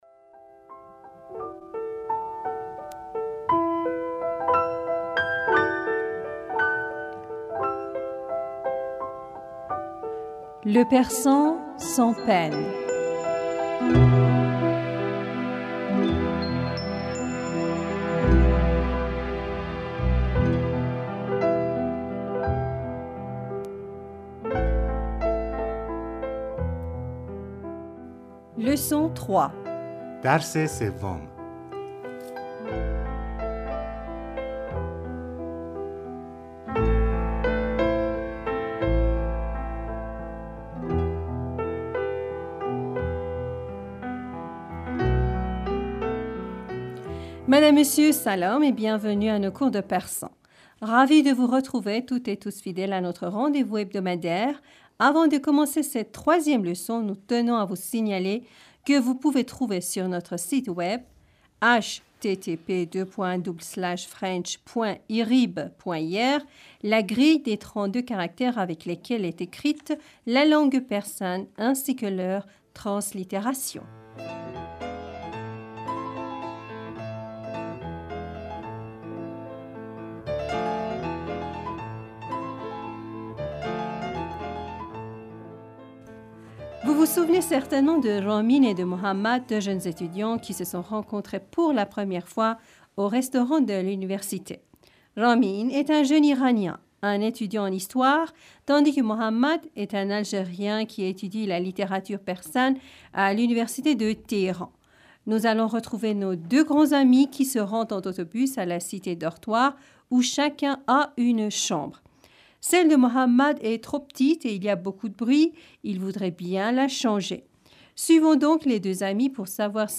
Nous les répétons deux fois, avec la traduction française.